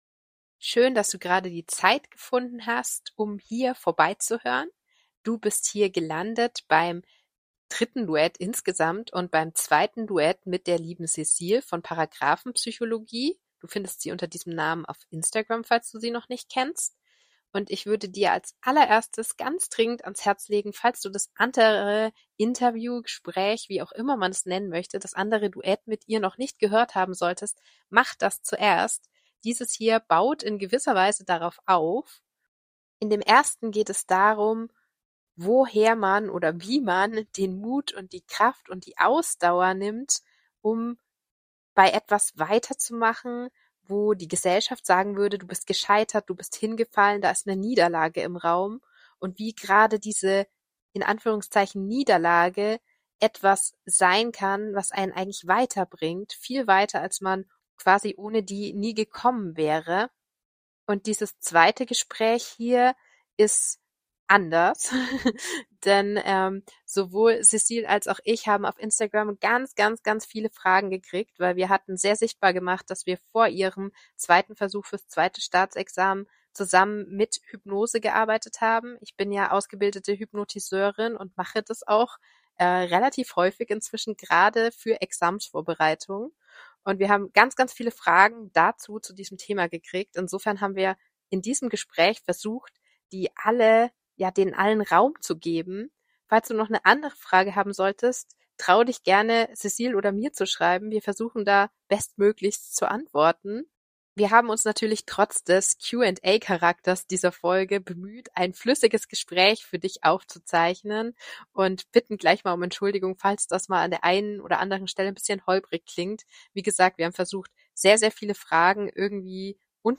Duett 003: Hypnose & Staatsexamen - (wie) geht denn das? Gespräch